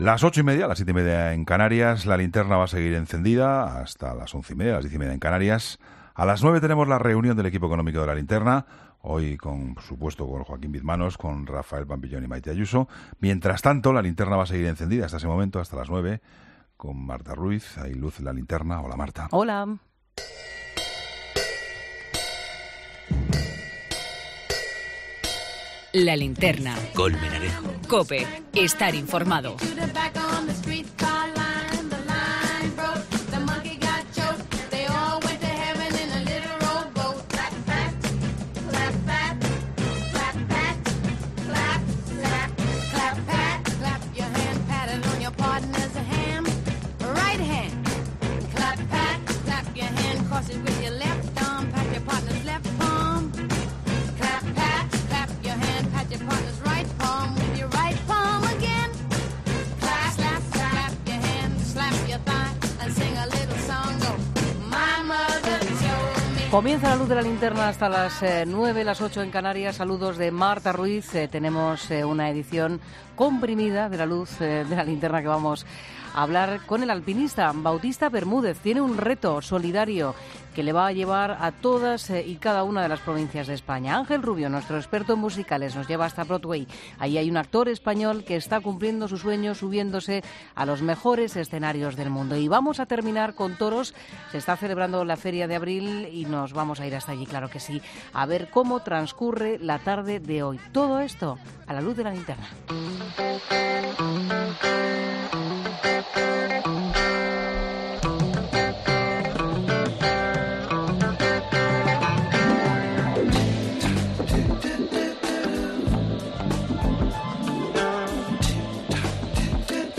Análisis de la Feria de Abril.